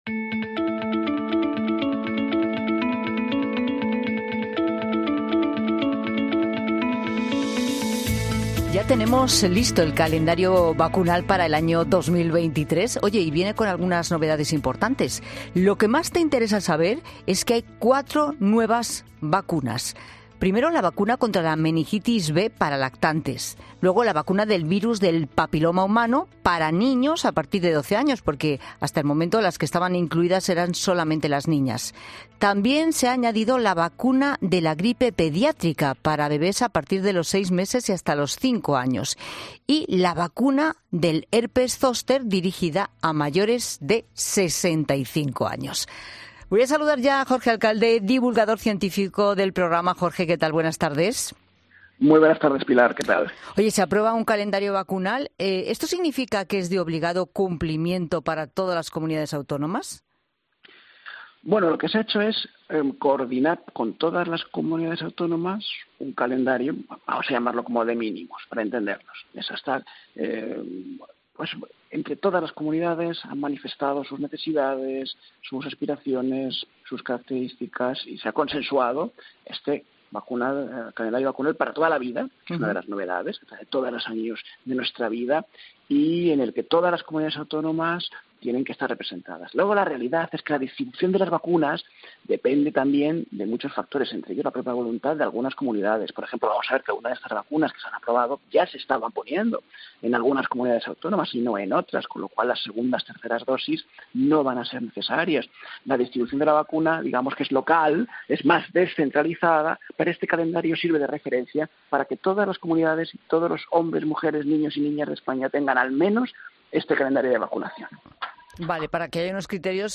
La investigadora